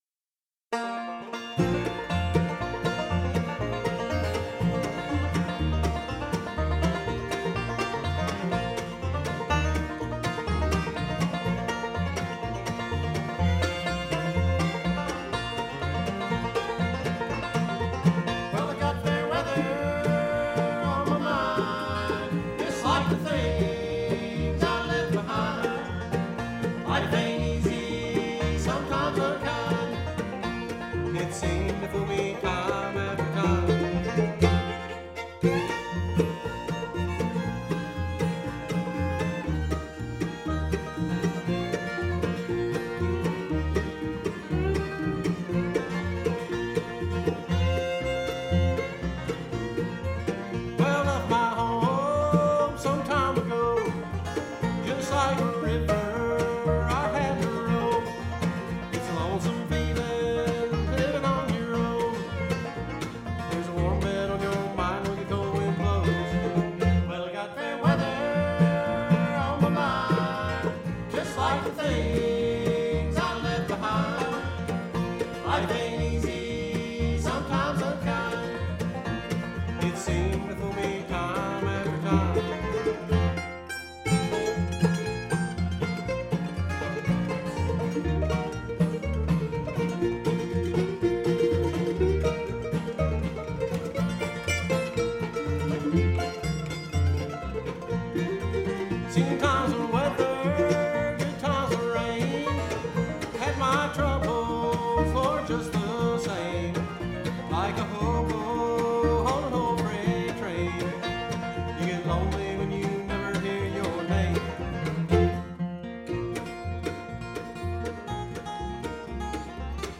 Guitar, lead and tenor vocals.
Mandolin, lead and tenor vocals.